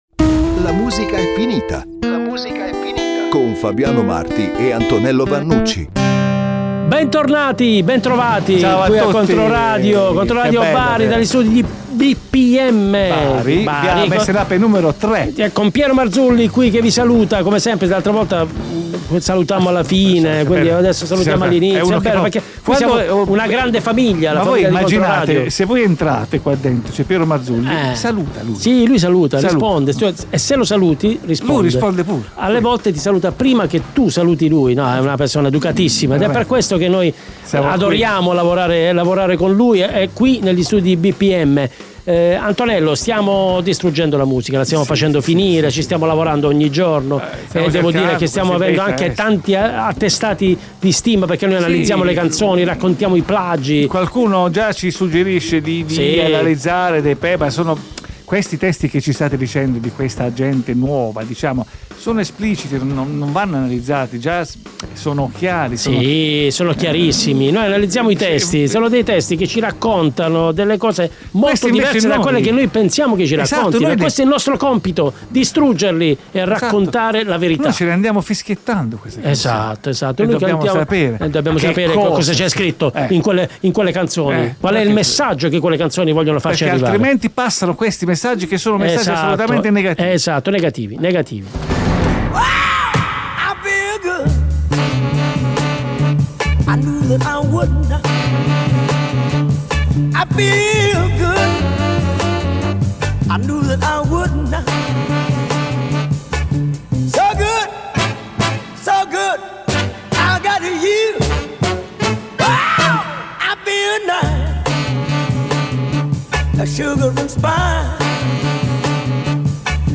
Tra aneddoti, plagi reali e immaginari, e brani internazionali reinterpretati in dialetto barese, il programma è un mix di cultura musicale e ironia, tutto da ascoltare. in esclusiva su Controradio 1.